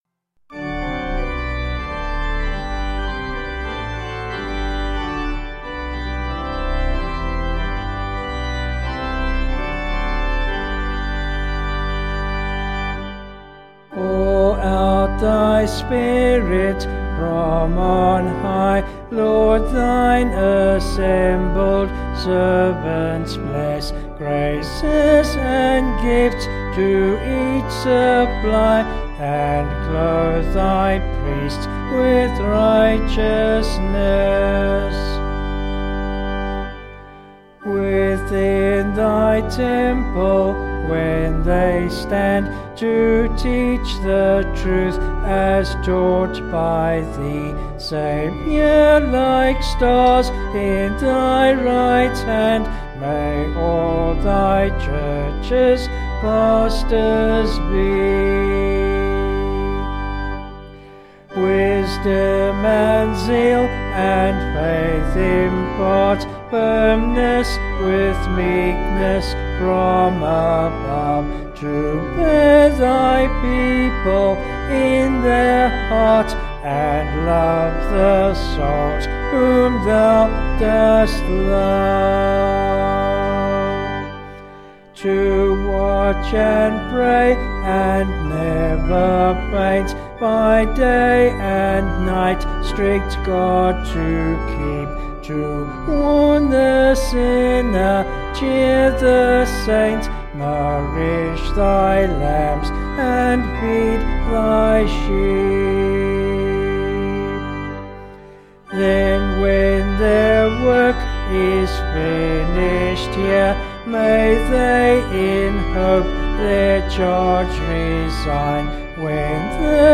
Vocals and Organ   264.6kb Sung Lyrics